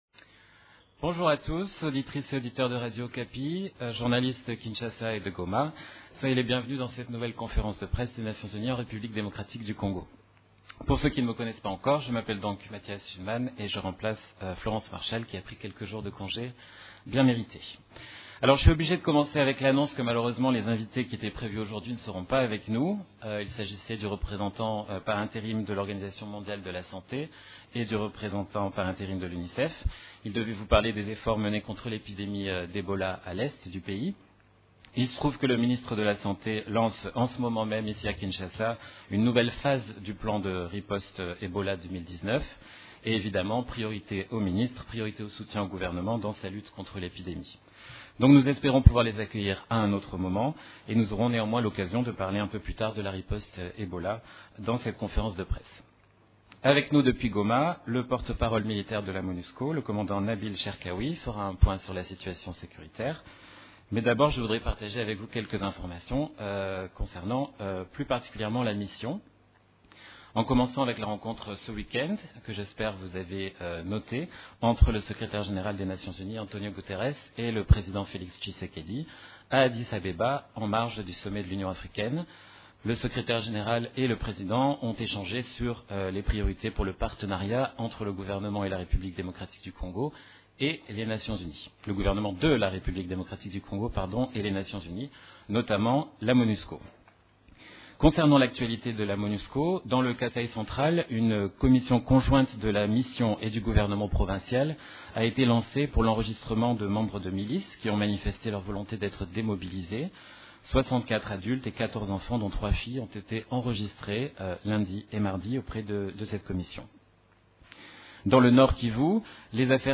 Conférence de presse du mercredi 13 février 2019
Ecoutez la première partie de cette conférence de presse: